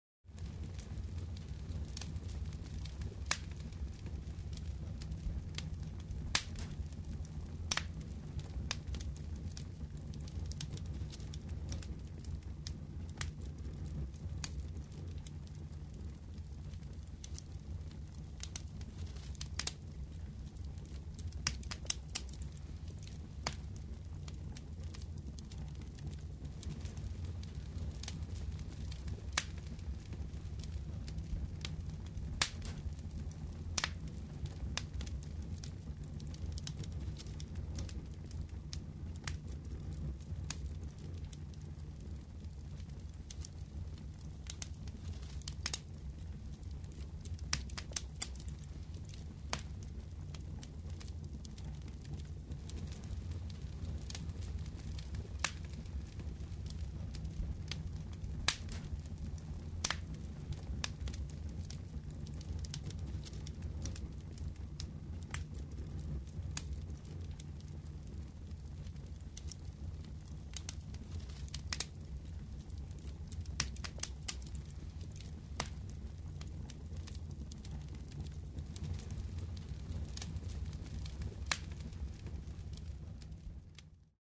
Подборка включает разные варианты: от спокойного потрескивания до яркого горения.
Костёр в ночи